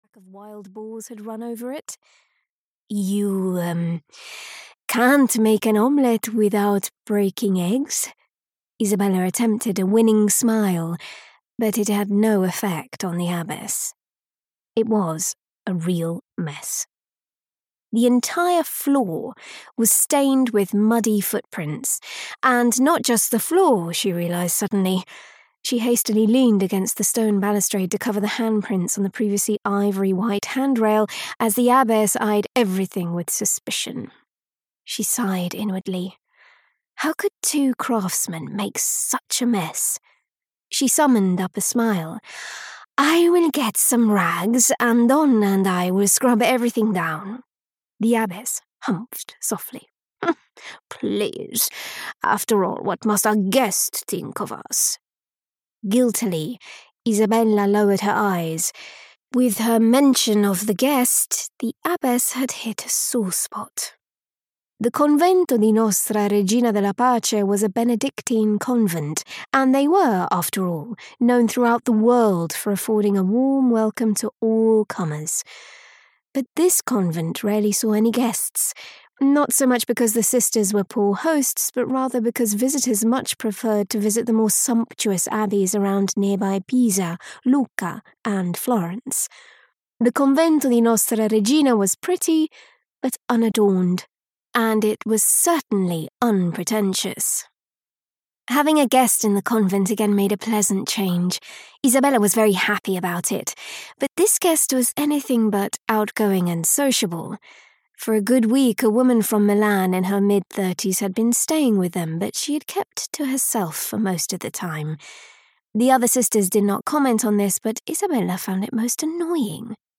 Death in the Cloisters (EN) audiokniha
Ukázka z knihy